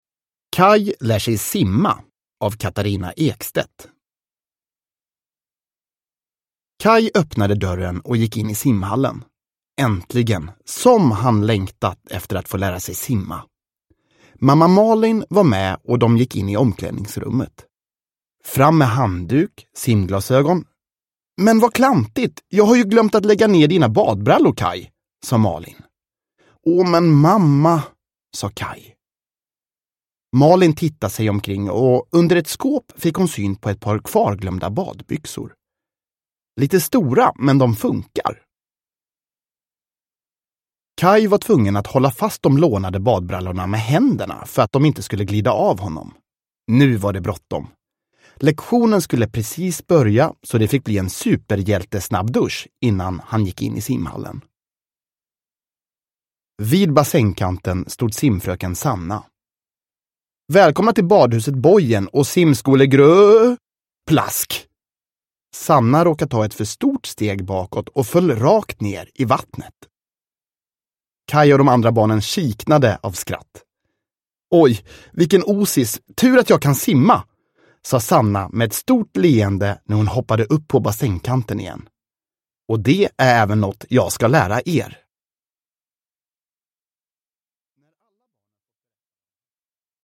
Kaj lär sig simma – Ljudbok